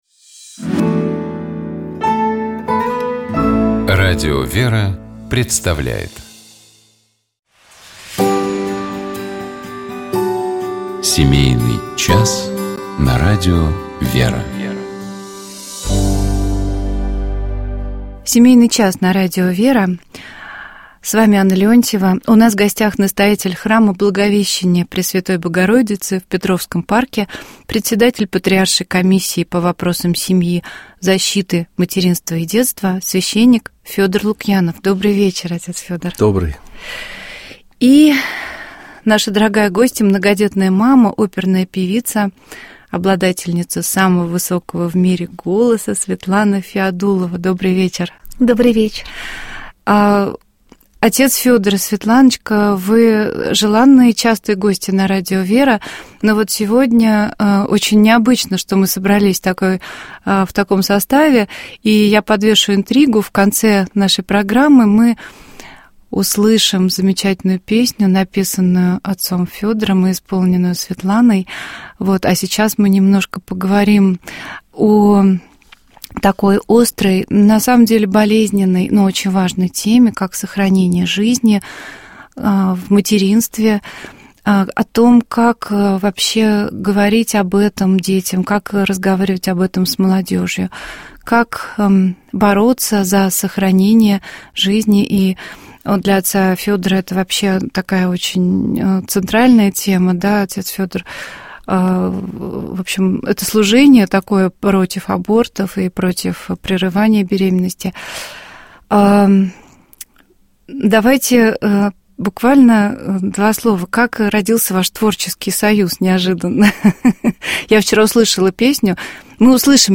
Всенощное Бдение. 16 марта (вечер 15 марта) 2025г.